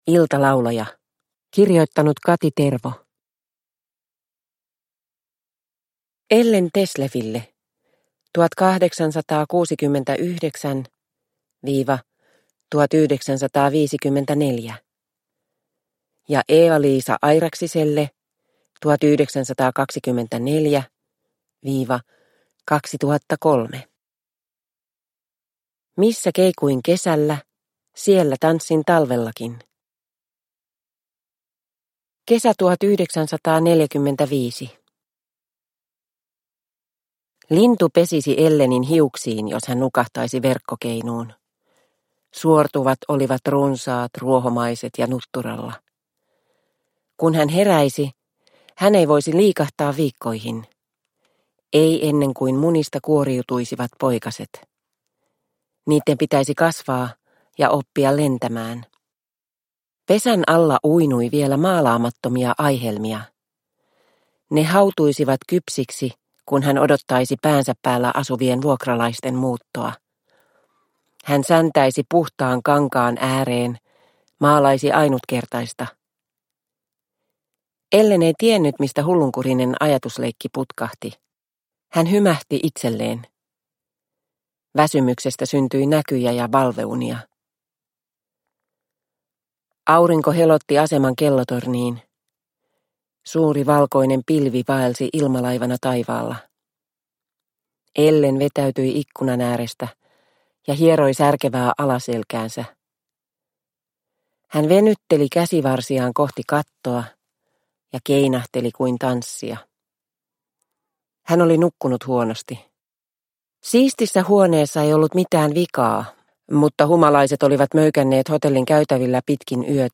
Iltalaulaja – Ljudbok – Laddas ner